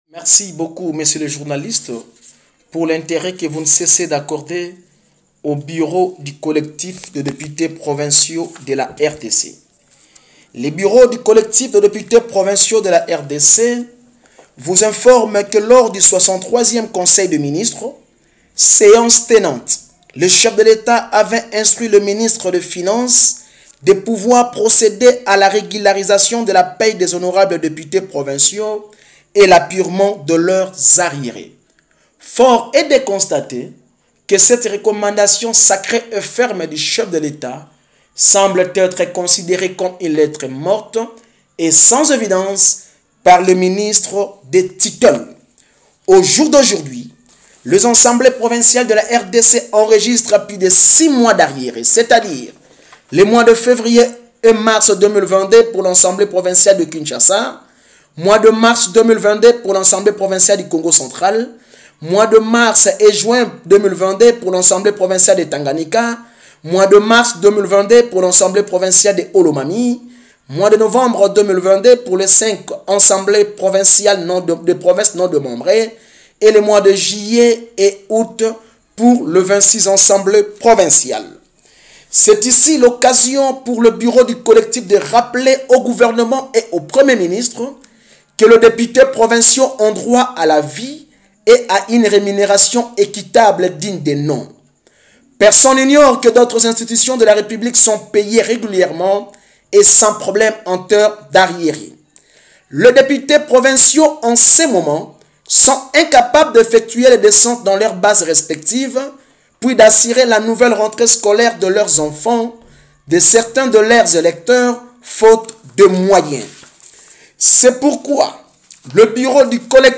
Dans cette déclaration lu par le rapporteur du collectif des députés provinciaux de la RDC, l’élu de Kenge dans la province du Kwango, ces derniers se disent regretter de constater que les députés nationaux peuvent toucher tout cet argent alors que en ce qui les concerne entant qu’élus provinciaux, cela fait six mois qu’ils accusent des arriérés.